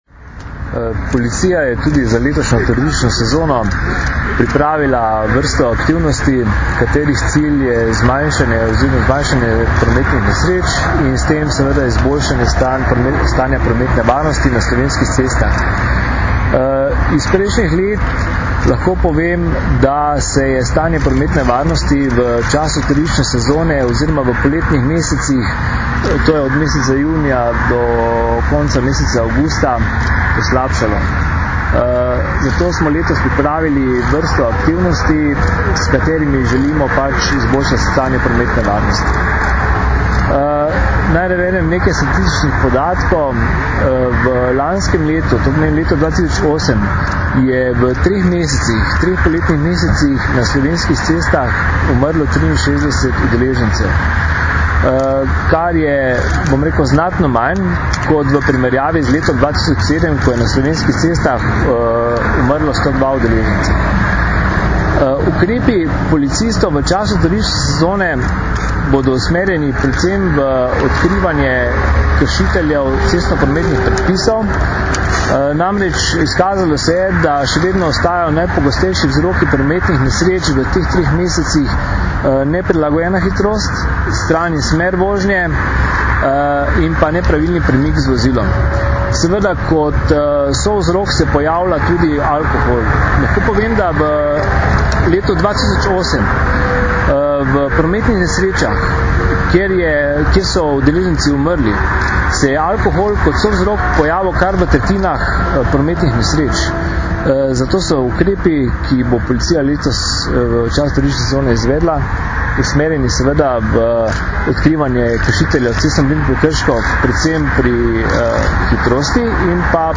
Na počivališču Barje smo danes, 22. junija 2009, predstavili aktivnosti policije ob napovedanih zgostitvah prometa, s katerimi želimo zagotoviti varnost v cestnem prometu med turistično sezono.